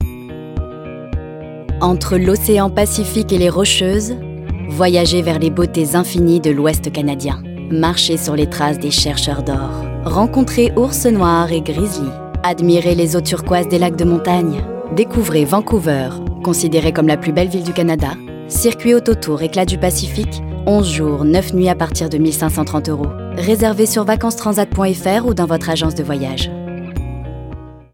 Bandes-son
Publicité Oxybulle